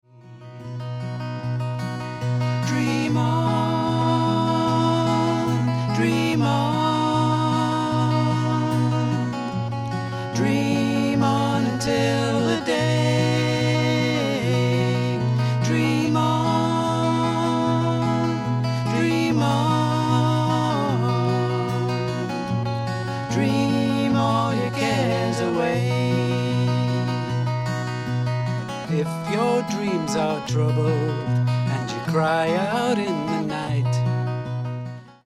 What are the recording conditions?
Ashington Folk Club - Singers, Musicians & Poets 01 June 2006